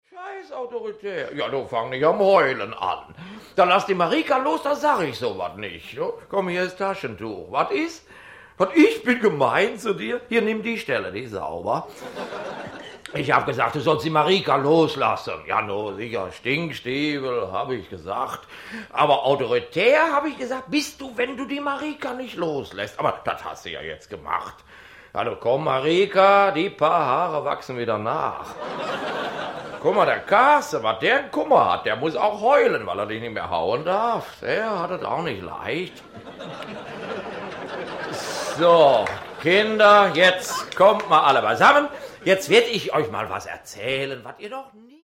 Jürgen von Manger (Sprecher)
Themenwelt Literatur Comic / Humor / Manga Humor / Satire